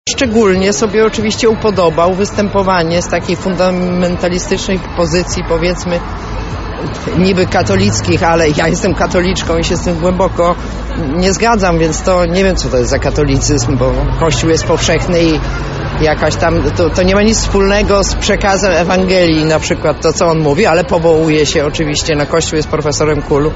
Odkąd został wojewodą lubelskim, to z każdą jego wypowiedzią płakać się chce – mówi jedna z uczestniczek protestu:
Protest